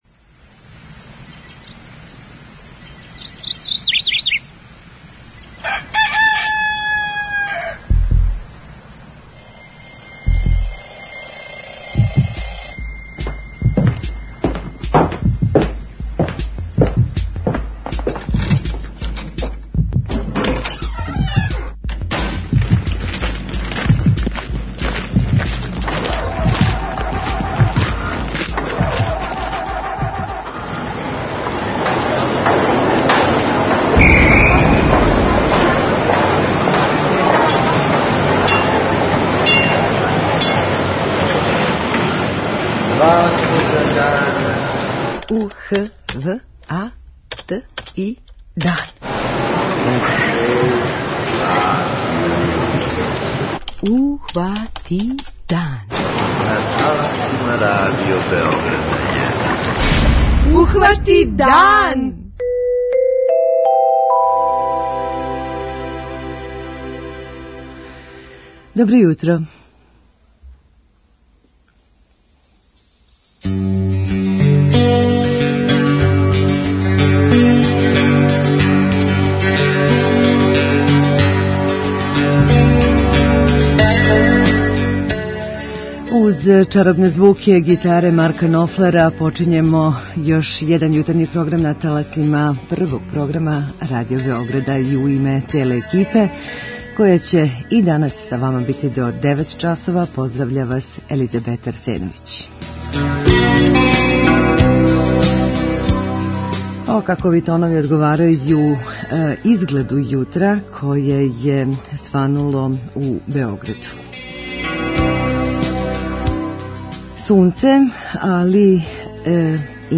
преузми : 32.36 MB Ухвати дан Autor: Група аутора Јутарњи програм Радио Београда 1!